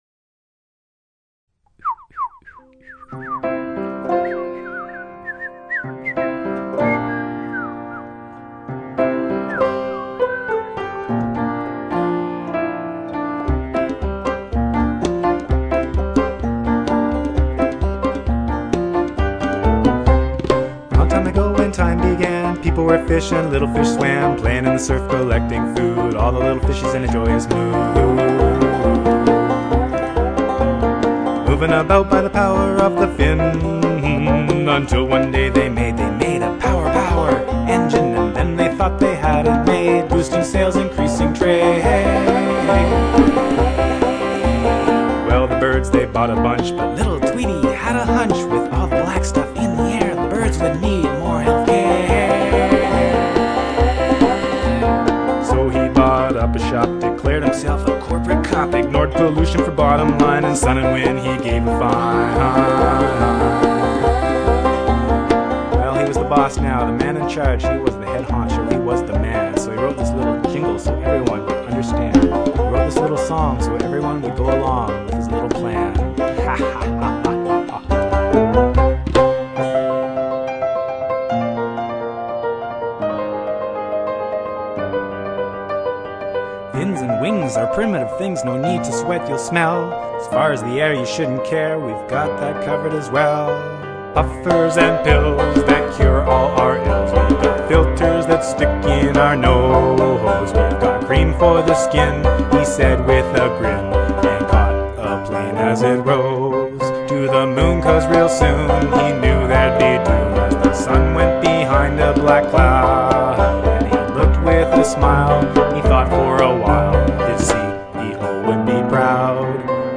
Piano, Vocals
Banjo
Harmony Vocals
Percussion